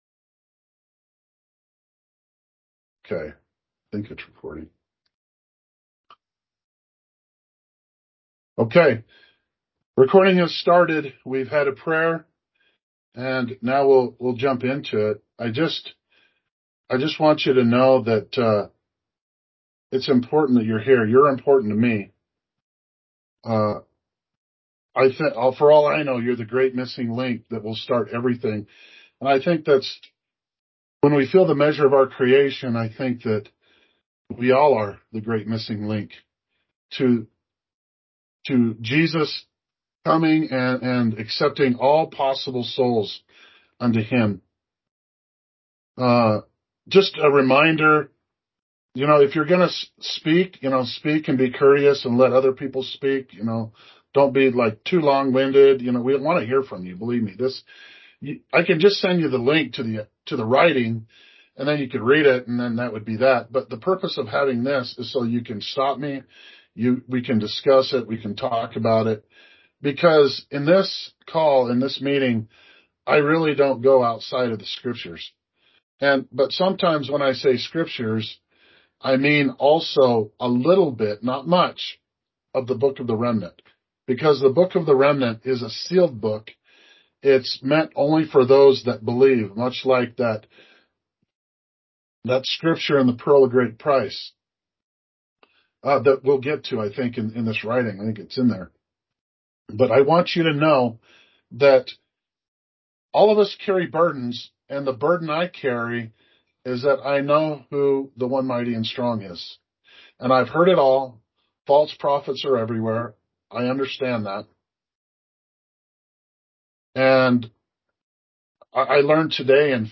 ***Download the Teams meeting scripture study (mp3 only) pertaining to this post***